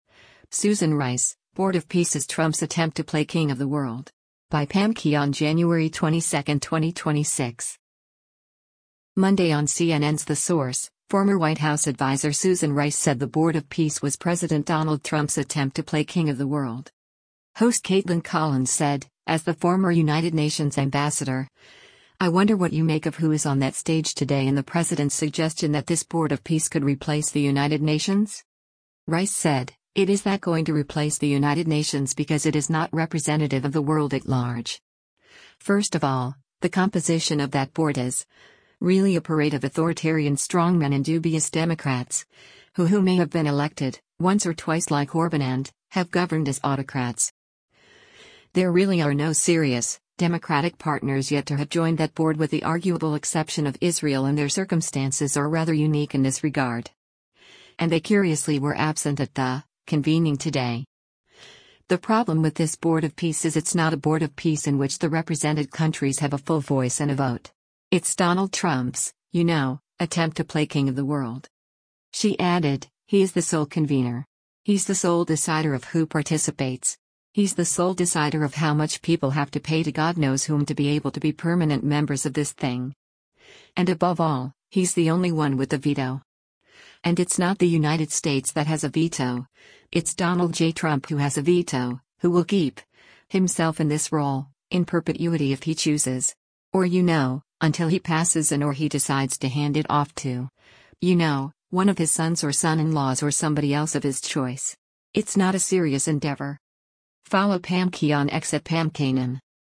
Monday on CNN’s “The Source,” former White House adviser Susan Rice said the Board of Peace was President Donald Trump’s attempt to play king of the world.